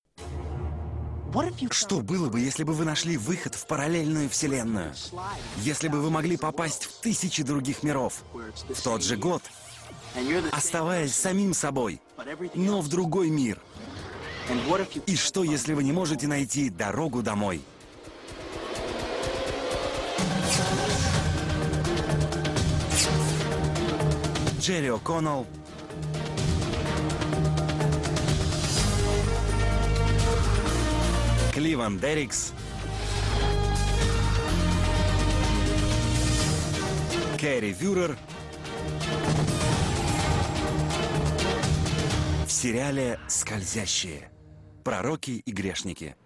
In der ersten Version der vierten Staffel ist die englische Tonspur leise unter dem russischen Sprecher zu hören.